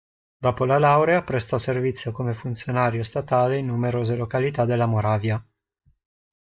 /lo.ka.liˈta/